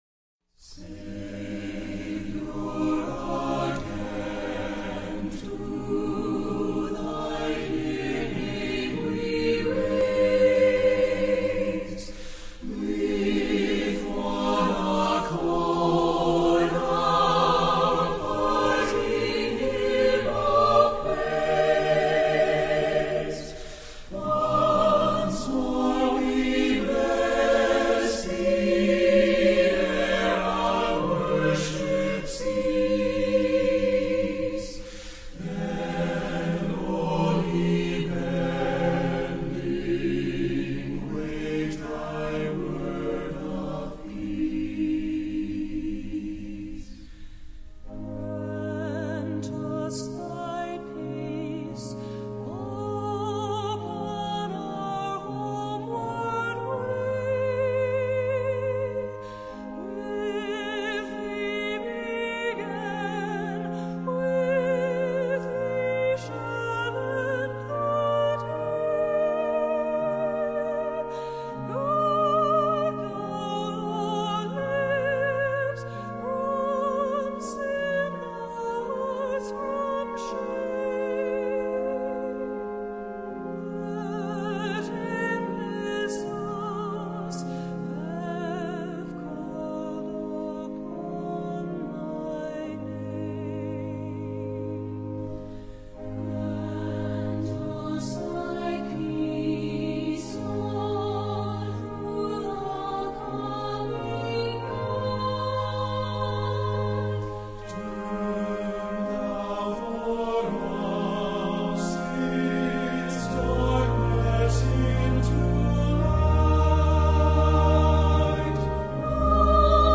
CLOSING HYMN Saviour, Again to Thy Dear Name              Edward John Hopkins (1818-1901)